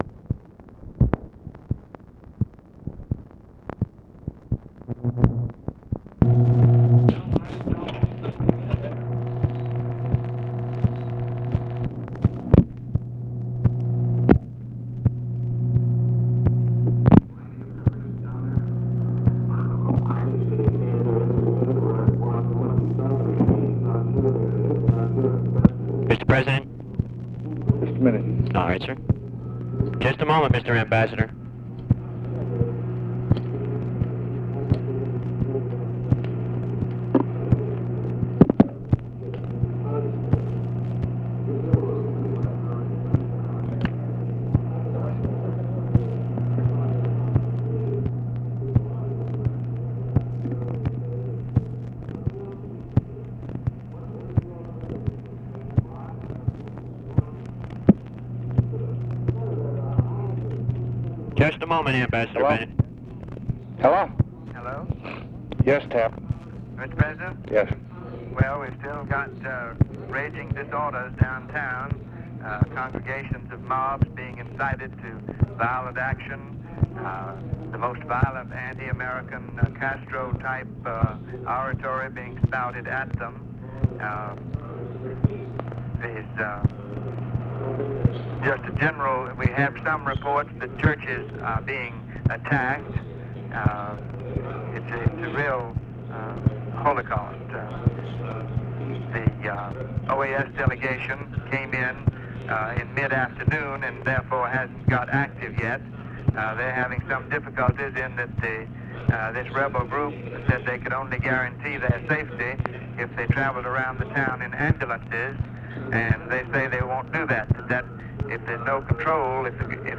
Conversation with W. TAPLEY BENNETT, MCGEORGE BUNDY, JOHN BARTLOW MARTIN and TELEPHONE OPERATORS, May 2, 1965
Secret White House Tapes